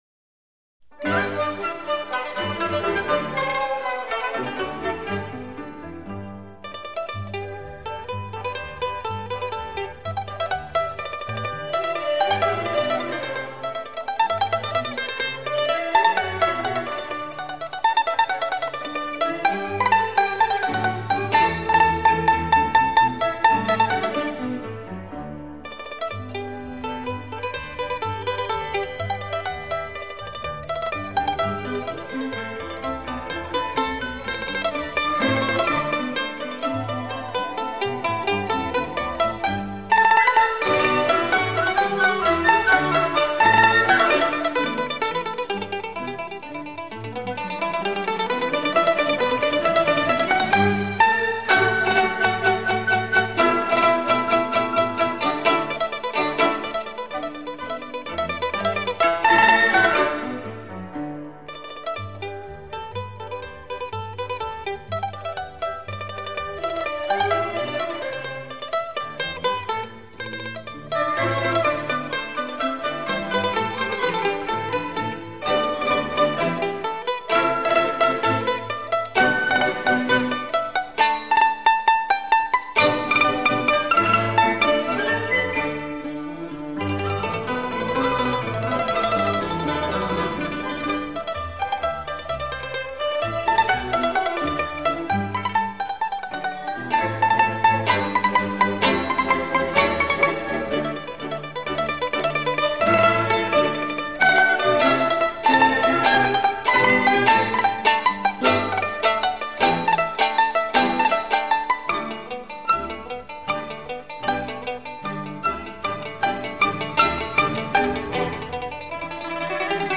The Best Chinnese Pluked Instruments Music